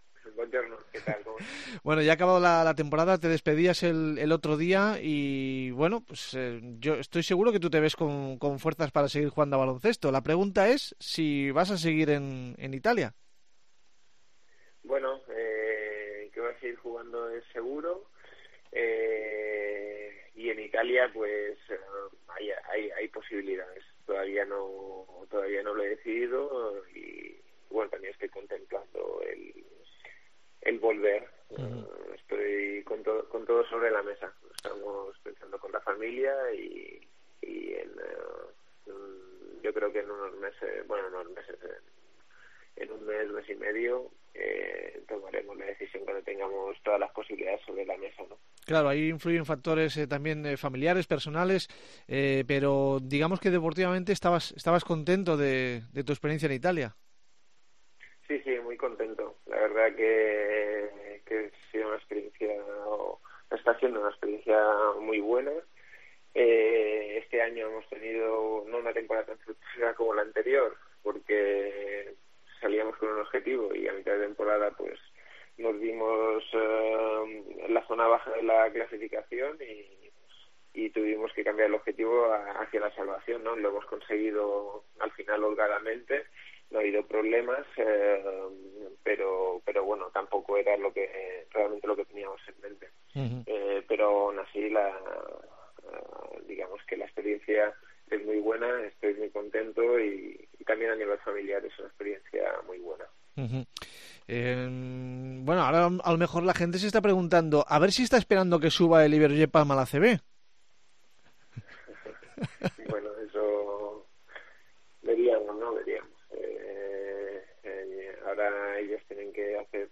A sus 36 años está a un buen nivel y con ganas de seguir tres o cuatro años más, en esta entrevista expresa su deseo de volver a jugar en casa antes de la retirada, está atento al playoff de ascenso del Iberojet Palma.